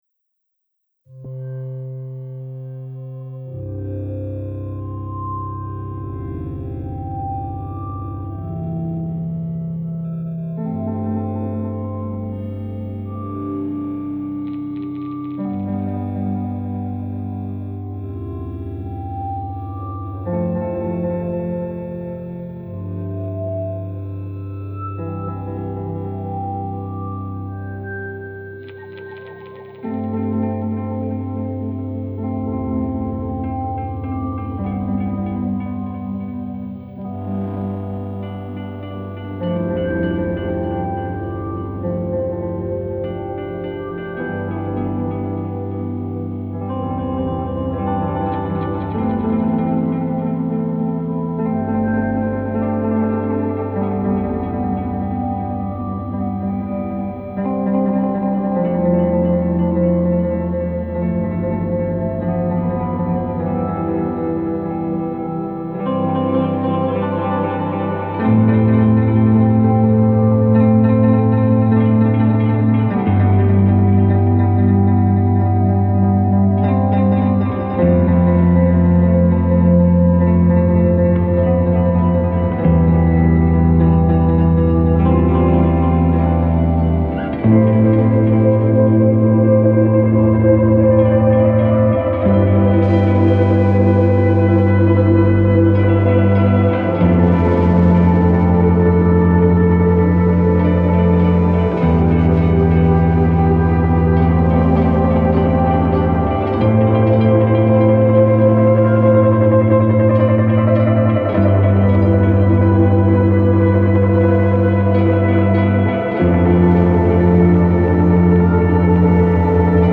A lot of bands can shove a wall of sound in your face.
instrumental rock